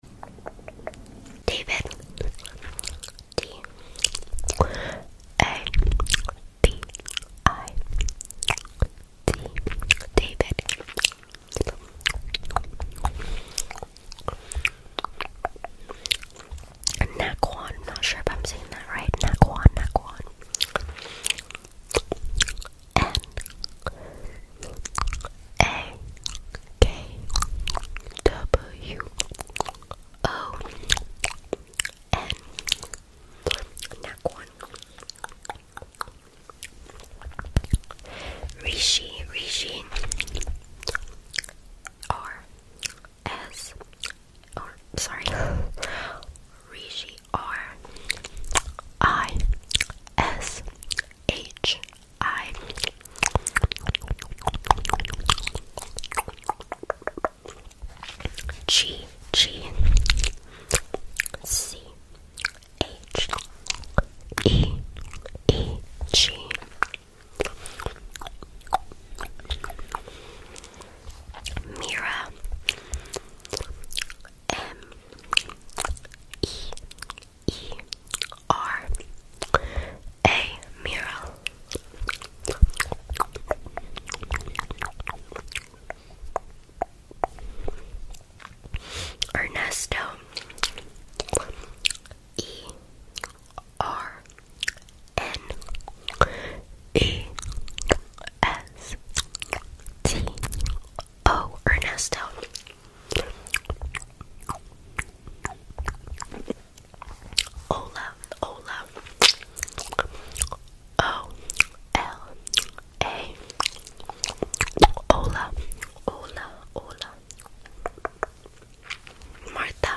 ASMR MOUTH SOUNDS 👅 sound effects free download